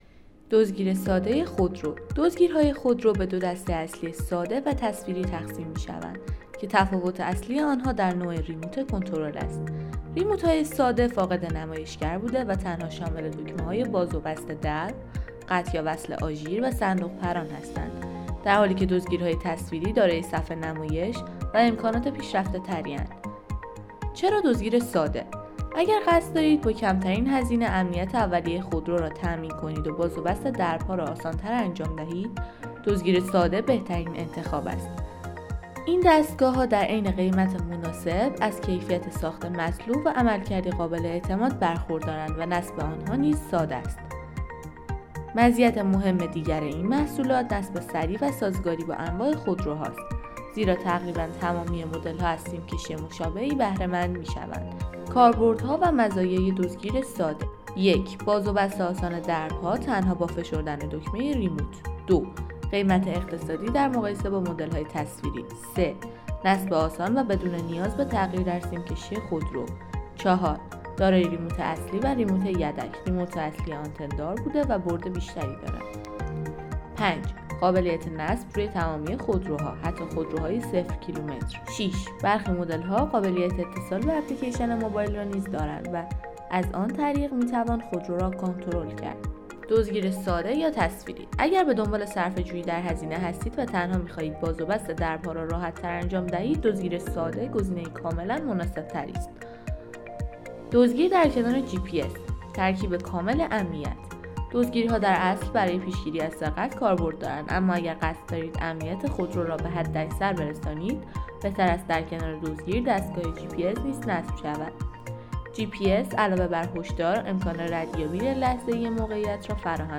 دزدگیر ساده خودرو - سیستم صوتی ماریامارکت
دزدگیر ساده.m4a